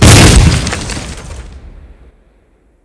GrenExpl10.wav